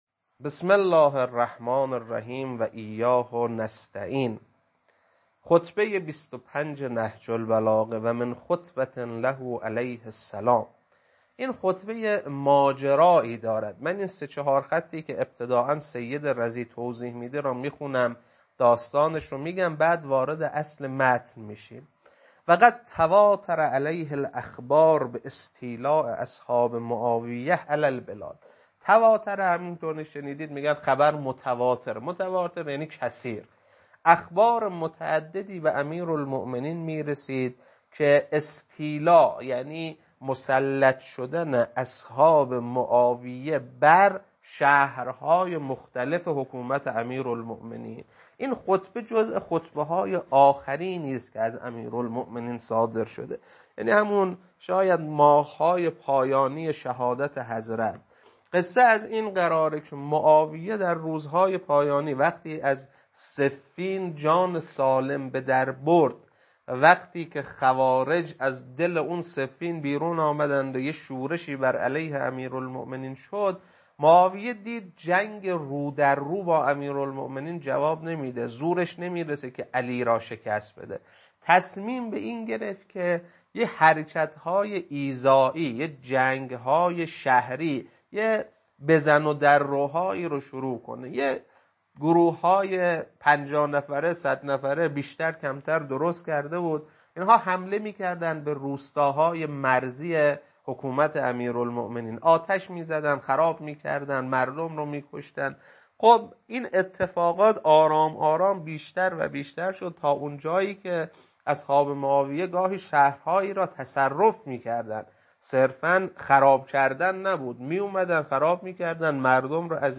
خطبه 25.mp3
خطبه-25.mp3